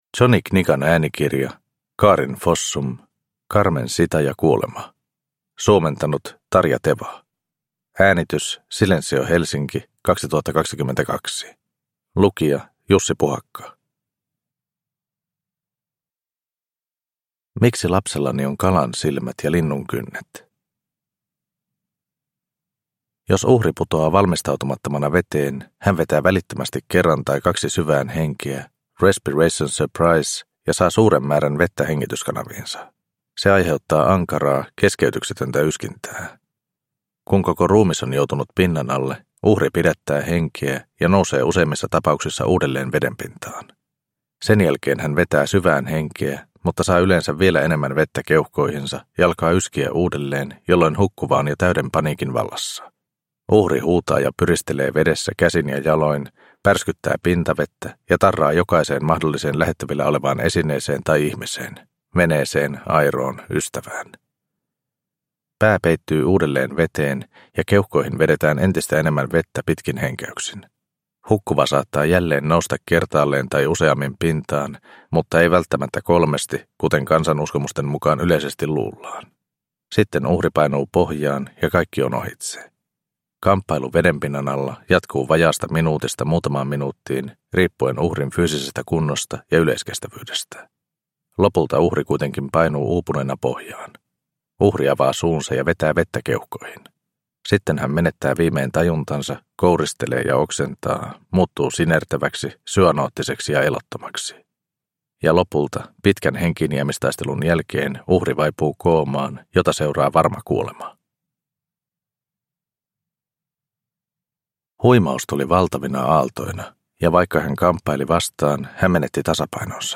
Carmen Zita ja kuolema – Ljudbok – Laddas ner